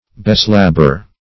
Beslabber \Be*slab"ber\